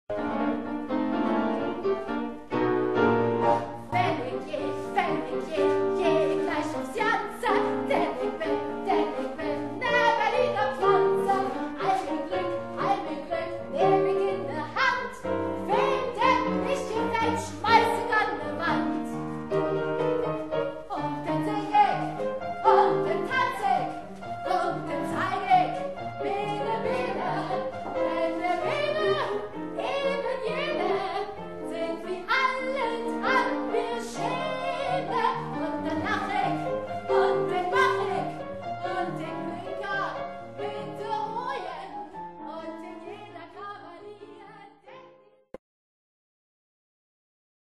3-Personen-Musical mit Klavierbegleitung